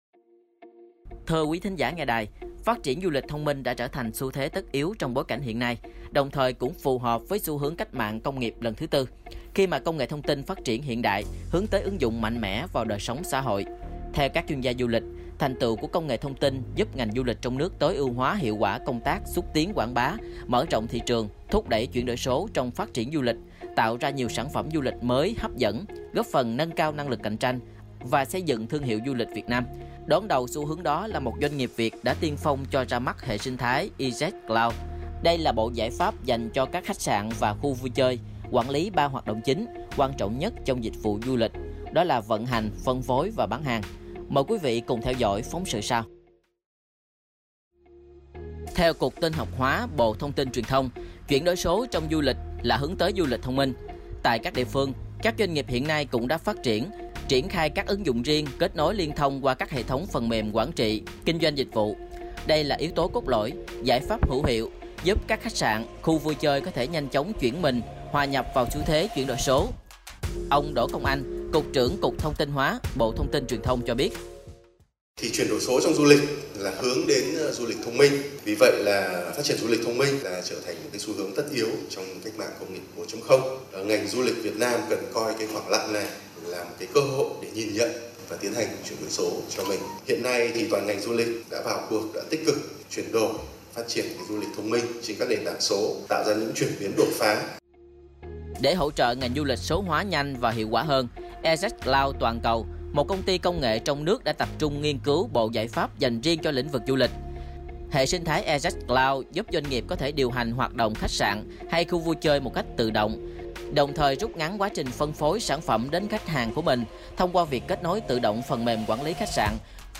File phát thanh